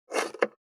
485,包丁,厨房,台所,野菜切る,咀嚼音,
効果音